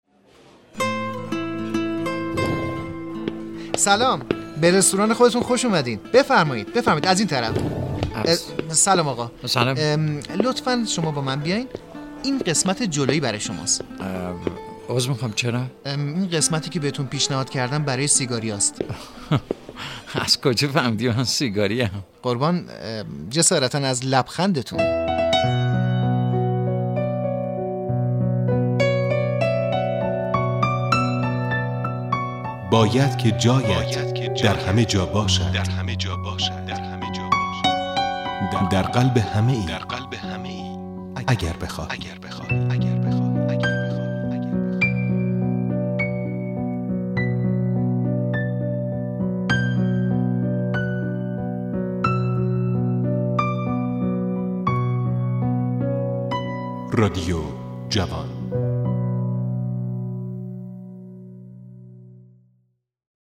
PSA سیگار
یک نمونه از آگهی های خدمات اجتماعی با موضوع سیگار کشیدن.